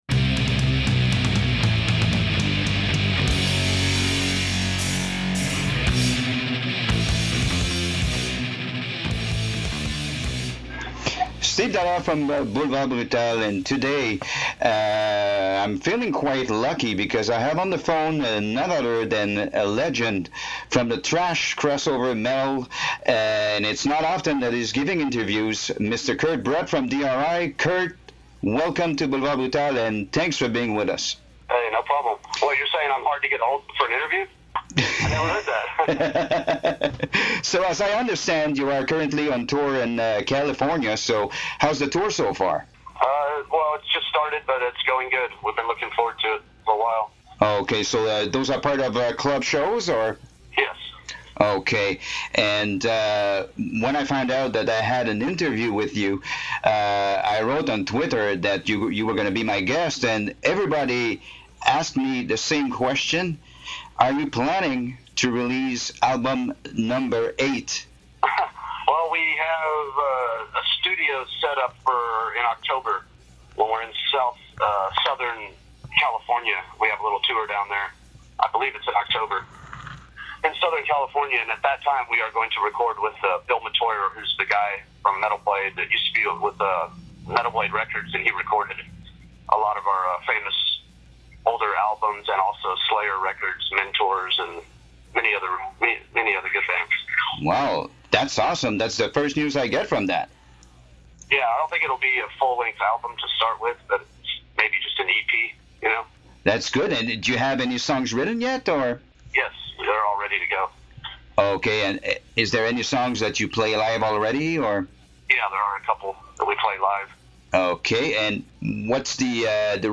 dri_interview_kurtbrecht.mp3